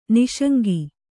♪ niṣaŋgi